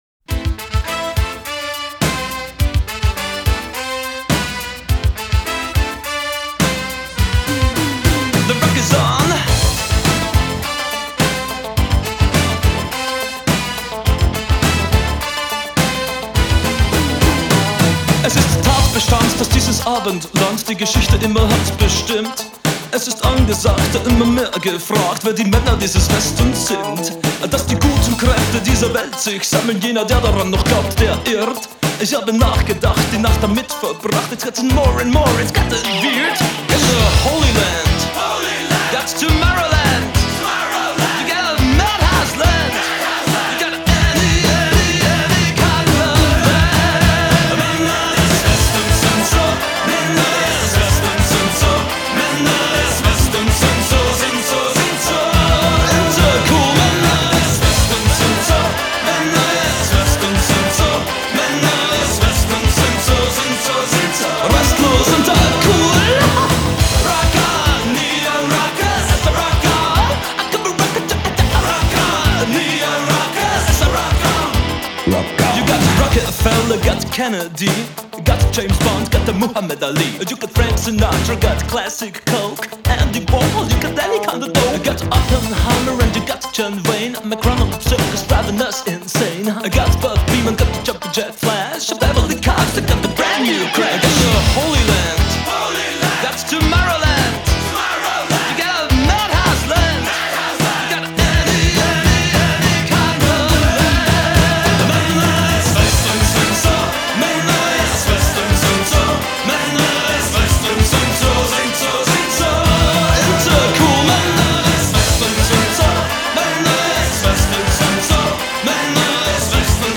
Genre: New Wave